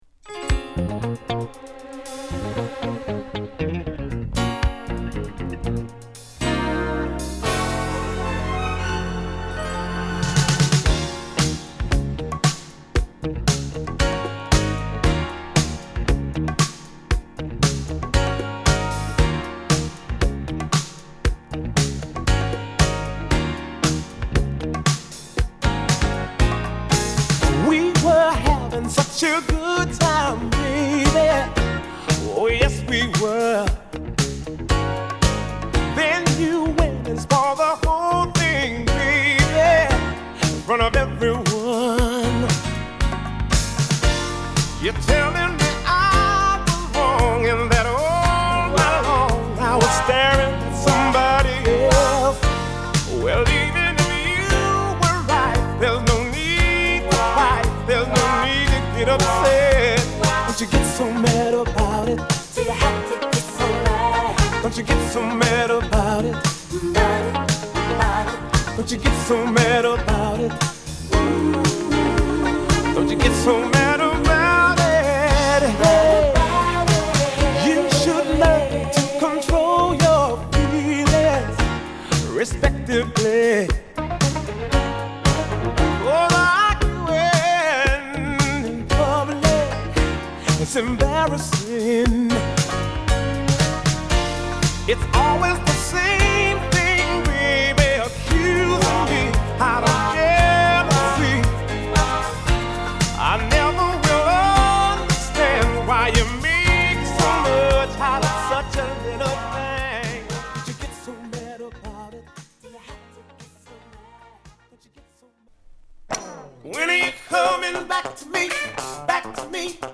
モダンファンク／ブギー